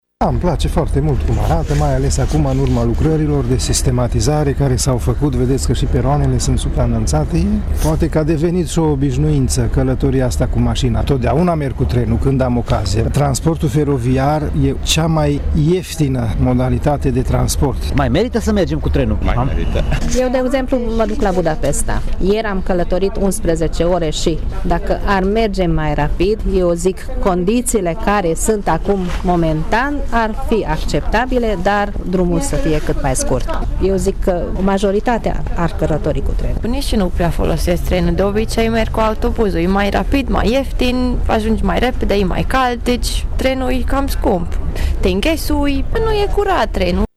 Călătorii care staționează în Gara Tîrgu-Mureș se declară mulțumiți de felul în care aceasta arată acum, după ce o parte a investiților a fost finalizată.